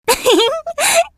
Amy rose giggle 2
amy-rose-giggle-2.mp3